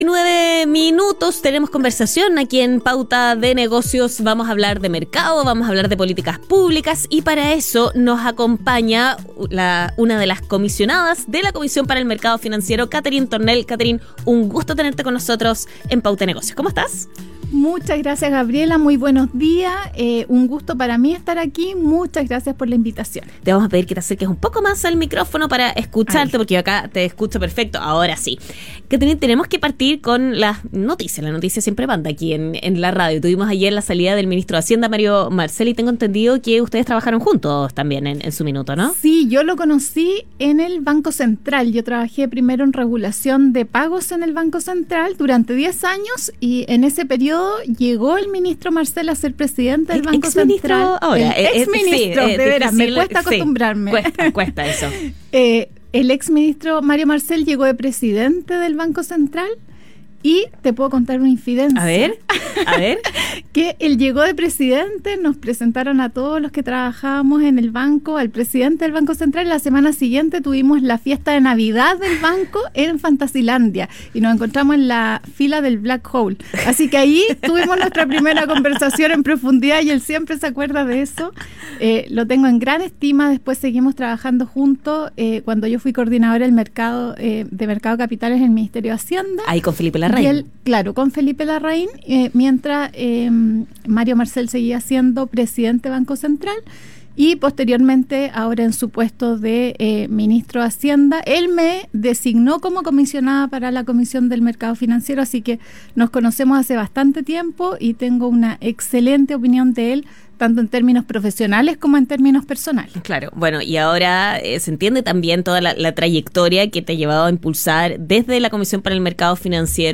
Talleres de desarrollo de mercado de la CMF w3-article-97899.html Revisa la entrevista de la Comisionada de la CMF, Catherine Tornel, quien explicó en Radio Pauta la agenda de desarrollo de mercado de la CMF y llamó a representantes del sector privado a participar de la jornada de talleres de trabajo. 22 de agosto de 2025.